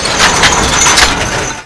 m134_reload1.wav